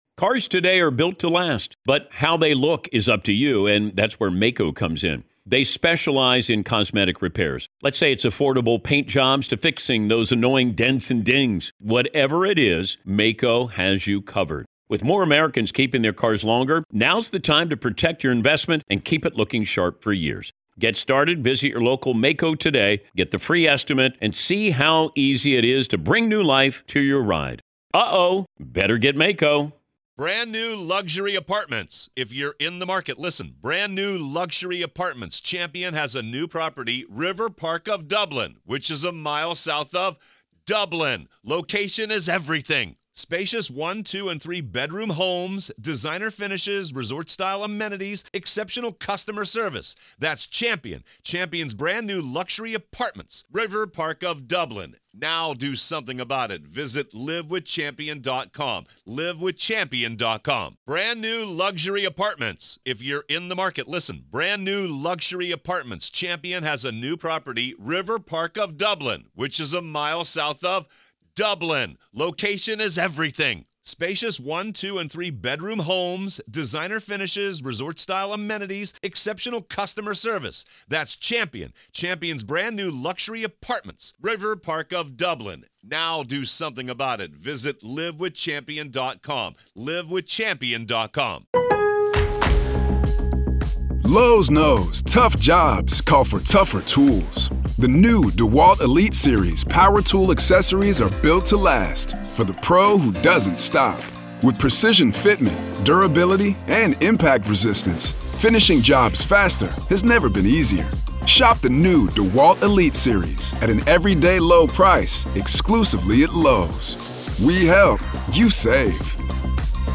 In the Red, White, Salute the Blue Flash-Back Weekend, we pay tribute to those who have dedicated their lives to law enforcement. This event celebrates our favorite interviews from the past, spotlighting the stories, experiences, and insights shared by our esteemed guests from...